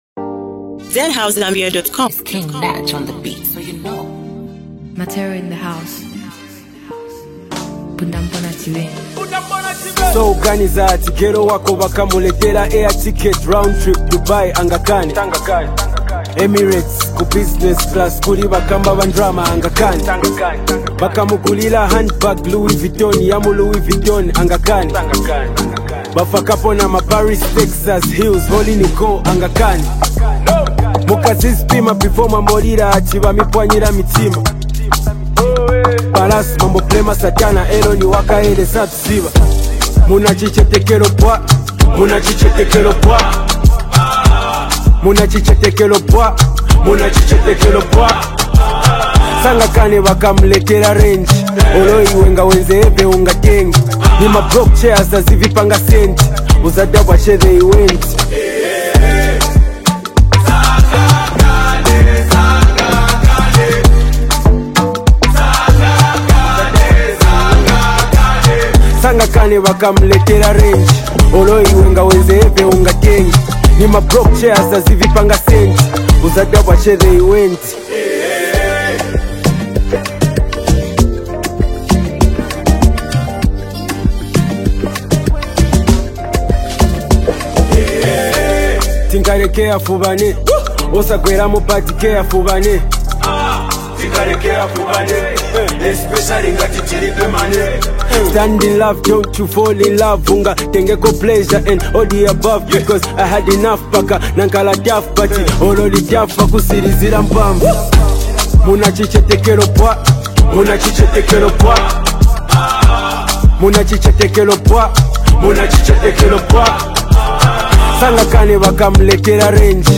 With catchy vibes and a message that resonates